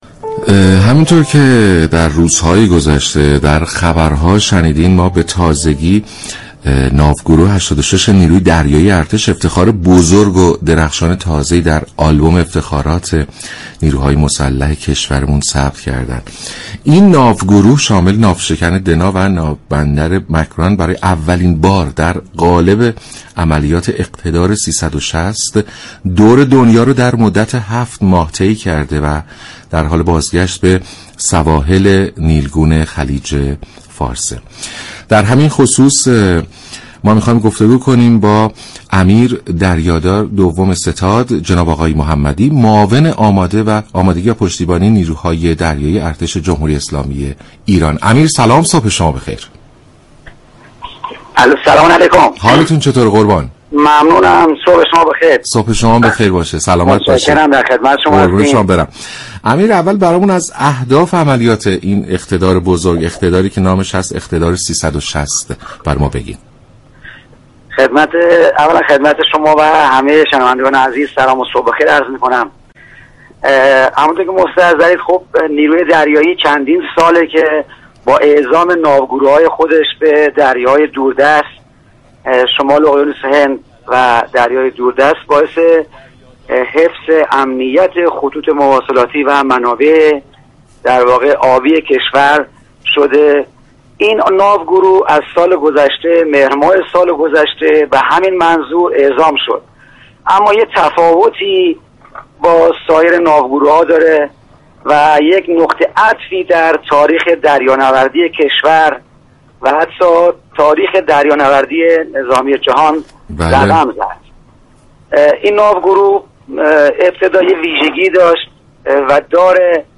به گزارش شبكه رادیویی ایران، امیردریادار دوم ستاد علیرضا محمدی معاون آمادگی پشتیبانی نیروی دریایی ارتش در برنامه «سلام صبح بخیر» رادیو ایران درباره ناوگروه 86 نیروی دریایی ارتش گفت: نیروی دریایی ارتش چند سالی است با اعزام ناوگروه های خود به دریاهای دور دست و شمال اقیانوس هند باعث حفظ امنیت خطوط مواصلاتی و منابع آبی شده است.